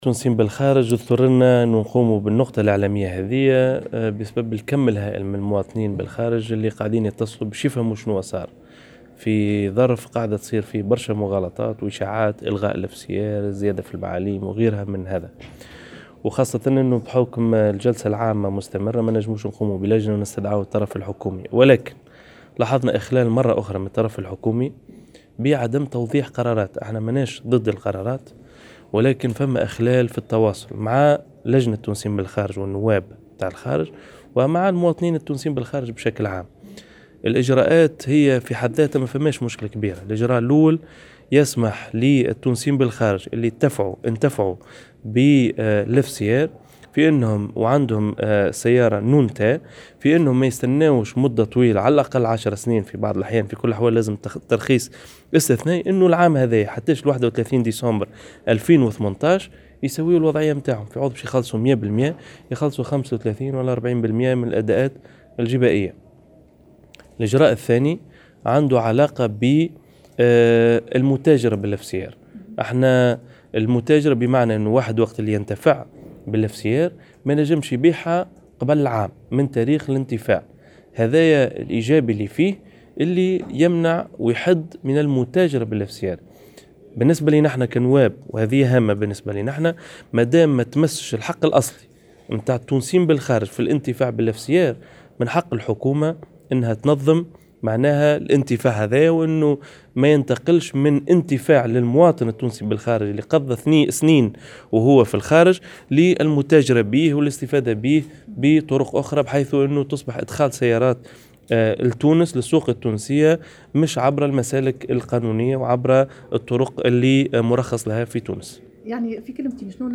وأوضح في تصريح لمراسلة "الجوهرة اف أم"، أن الحكومة ارادت في المقابل، وضع حد للمتاجرة بهذا النظام دون المس من الحق الأصلي للتونسيين بالخارج للانتفاع بهذا النظام بعد عودتهم النهائية.